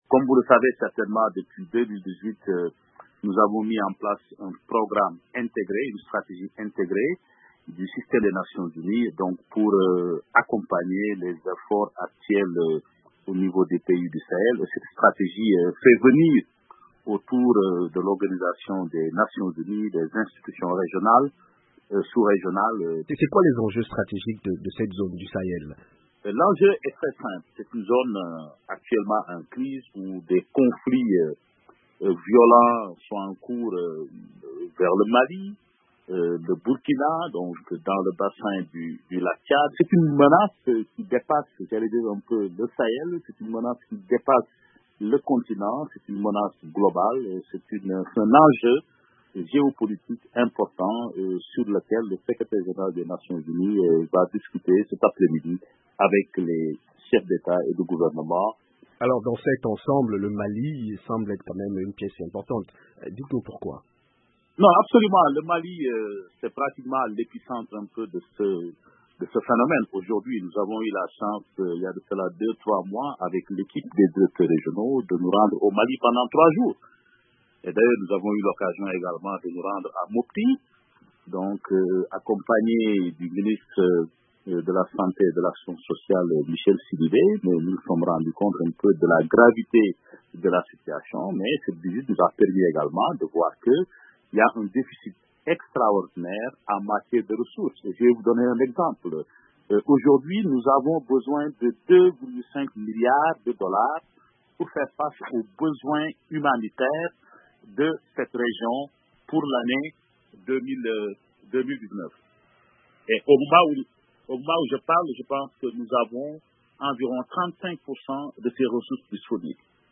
En marge de la 74ème Assemblée générale de l’ONU a lieu une rencontre dédiée au Mali et au Sahel organisée par le secrétaire général Antonio Guterres, sur les enjeux liés à cette région.